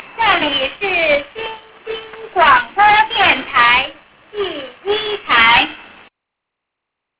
Each channel identifies itself at the beginning of the broadcasts as "the third" or "the fourth" program.